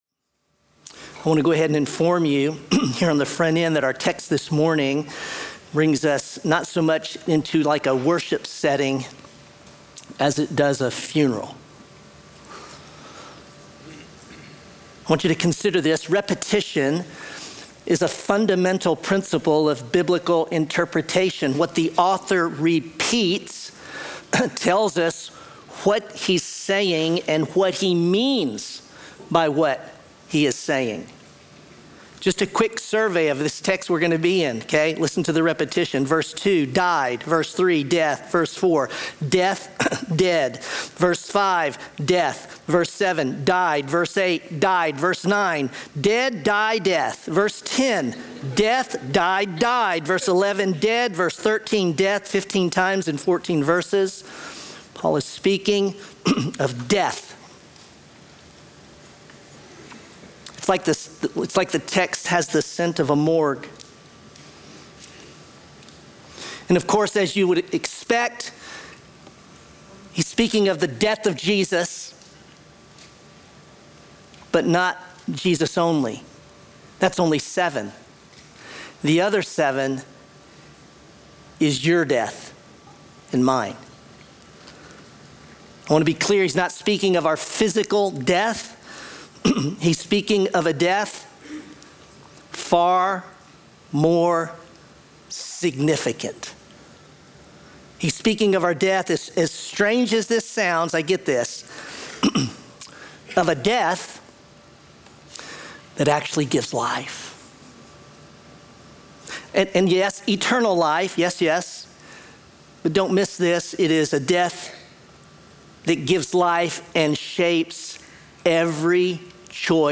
Sermon Romans: Righteousness Received